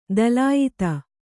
♪ tulyate